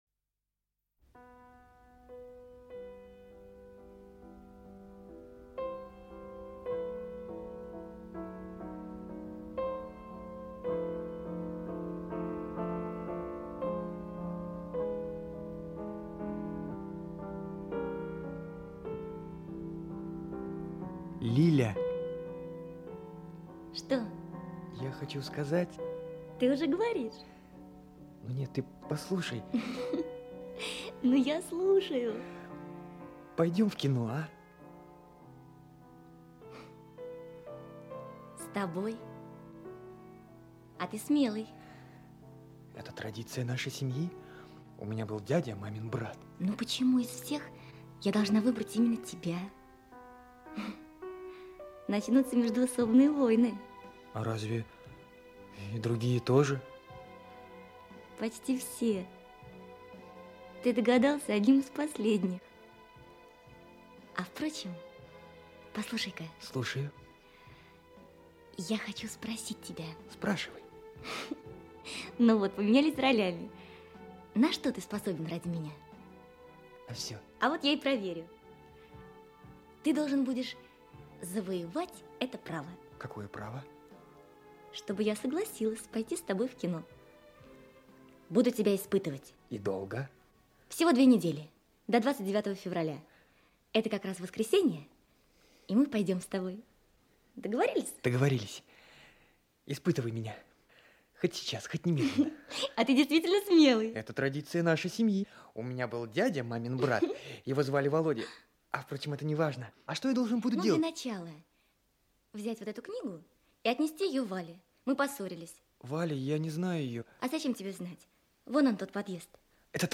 Аудиокнига Пойдем в кино?
Автор Анатолий Алексин Читает аудиокнигу Актерский коллектив.